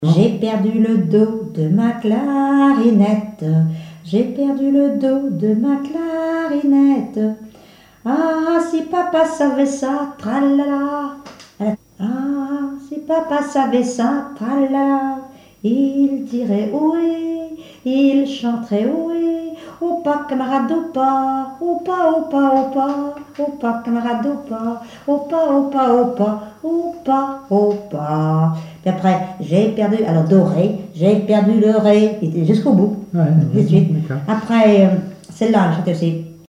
Mémoires et Patrimoines vivants - RaddO est une base de données d'archives iconographiques et sonores.
Enfantines - rondes et jeux
Genre énumérative
Pièce musicale inédite